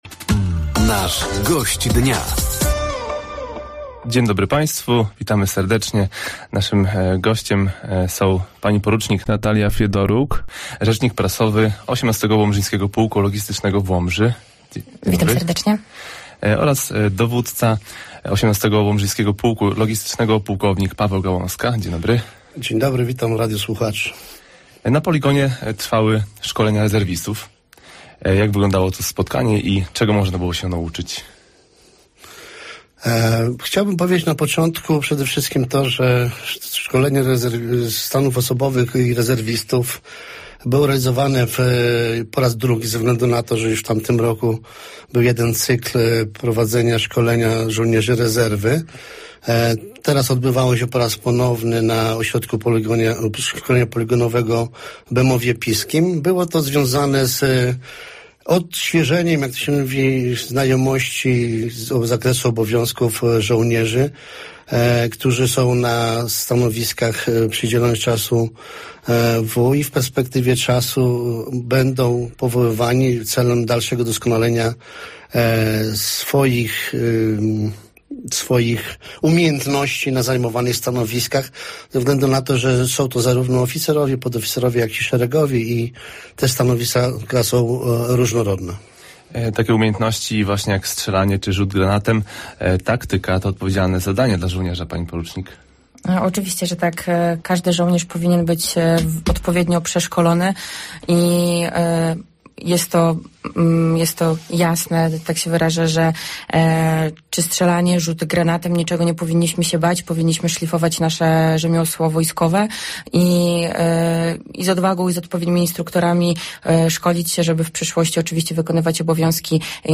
Rozmowa dotyczyła planów rozwojowych oraz kandydatów na żołnierzy.